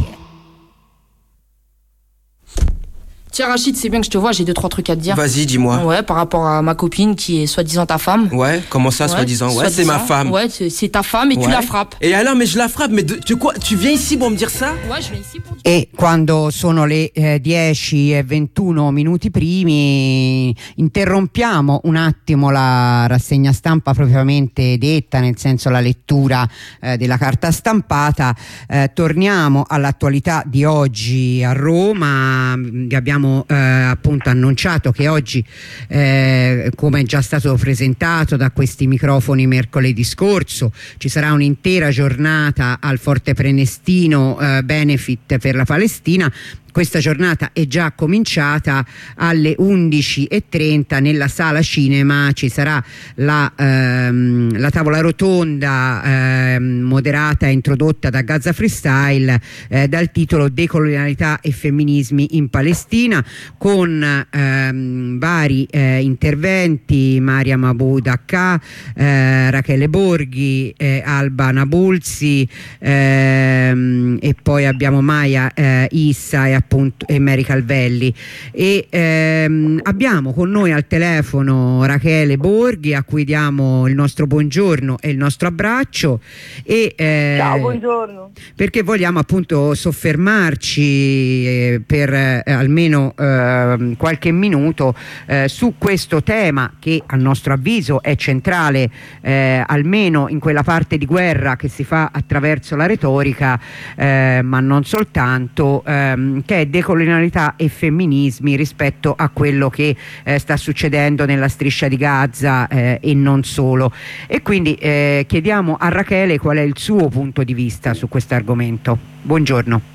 Lettura del comunicato